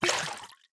Minecraft Version Minecraft Version 1.21.4 Latest Release | Latest Snapshot 1.21.4 / assets / minecraft / sounds / item / bottle / empty2.ogg Compare With Compare With Latest Release | Latest Snapshot